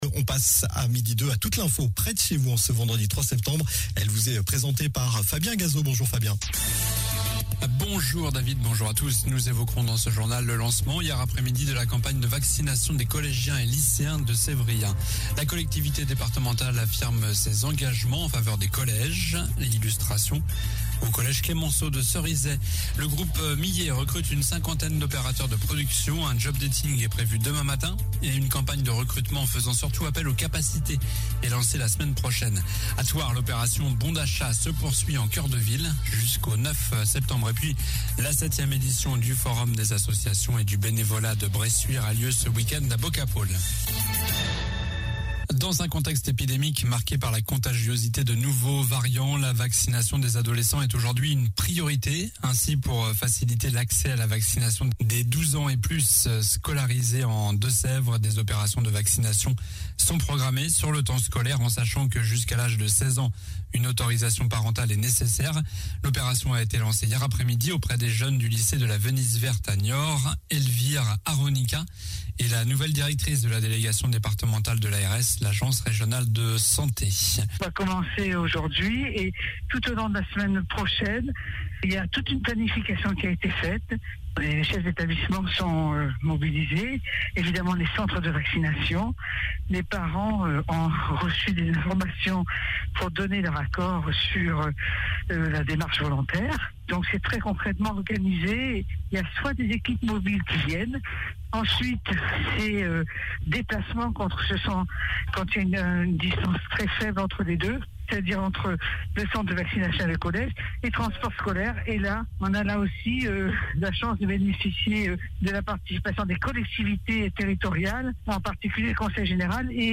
Journal du vendredi 03 septembre (midi)